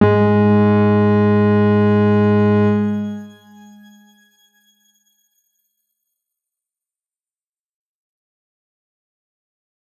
X_Grain-G#2-pp.wav